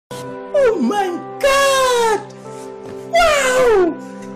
meme sound effects